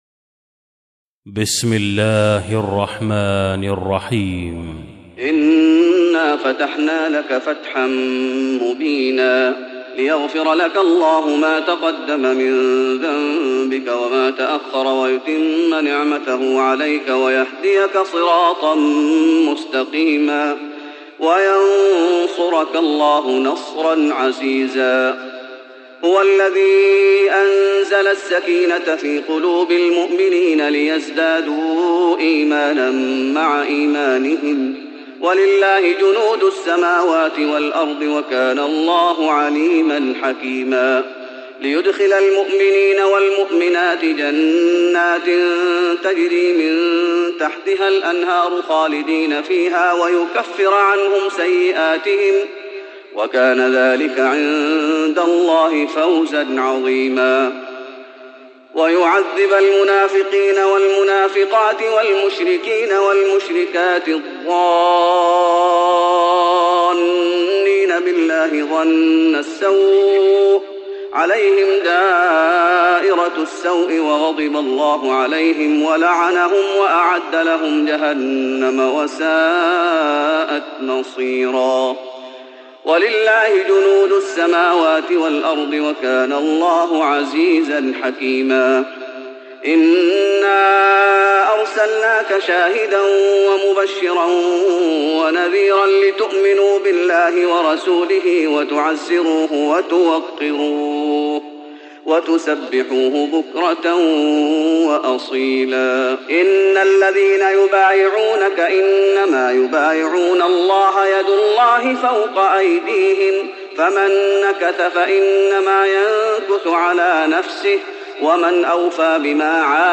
تراويح رمضان 1415هـ من سورة الفتح Taraweeh Ramadan 1415H from Surah Al-Fath > تراويح الشيخ محمد أيوب بالنبوي 1415 🕌 > التراويح - تلاوات الحرمين